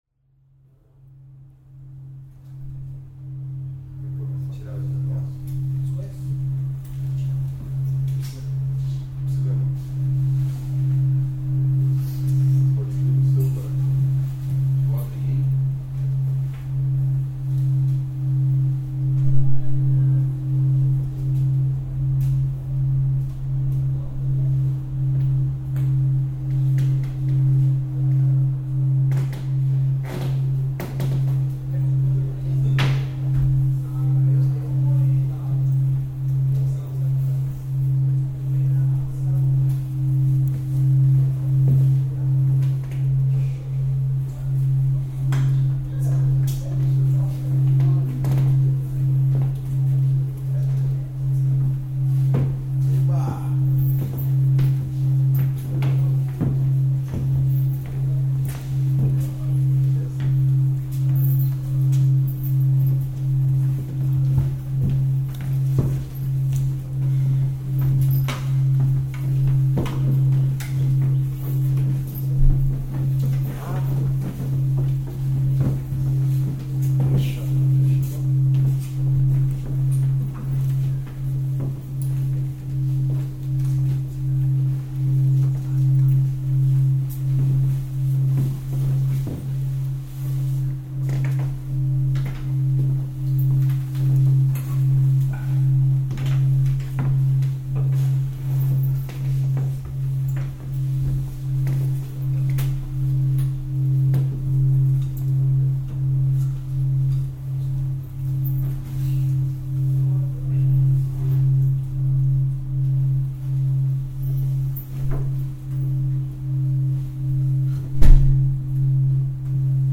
no Festival Bigorna (19/06/2016)